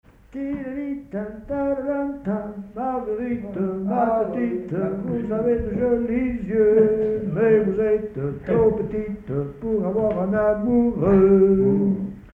danse : polka
circonstance : bal, dancerie
Pièce musicale inédite